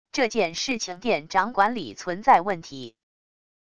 这件事情店长管理存在问题wav音频生成系统WAV Audio Player